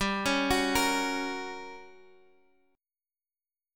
GmM7b5 Chord